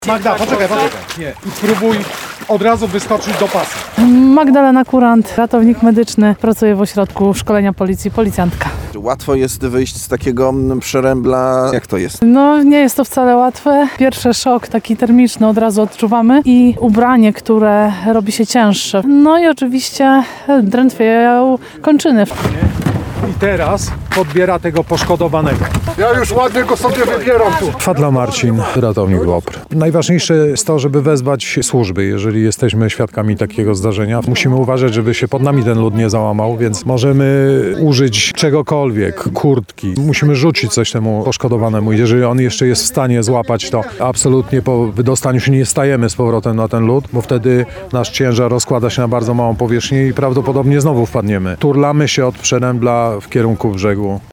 W sobotę (24.01) nad Zalewem Zembrzyckim w Lublinie strażacy, policjanci i WOPR-owcy szkolili się z ratowania osób, które wpadną do lodowatej wody.